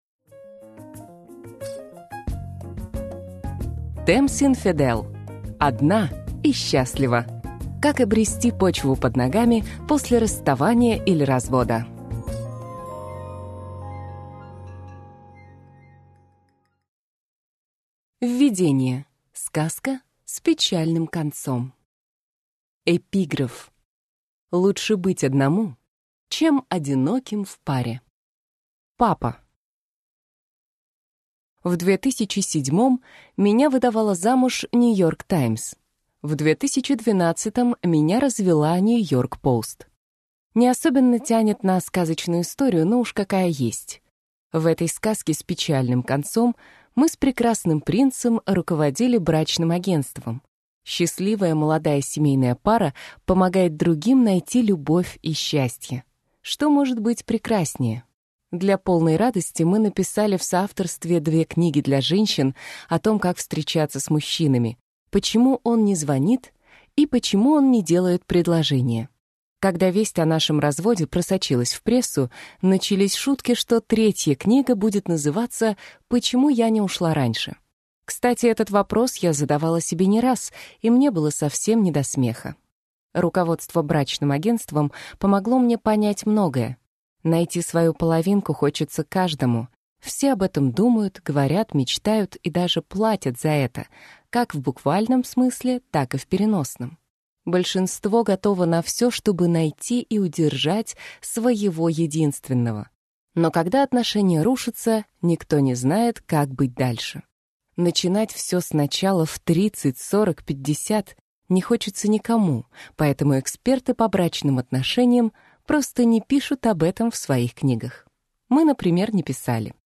Аудиокнига Одна и счастлива: Как обрести почву под ногами после расставания или развода | Библиотека аудиокниг